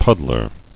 [pud·dler]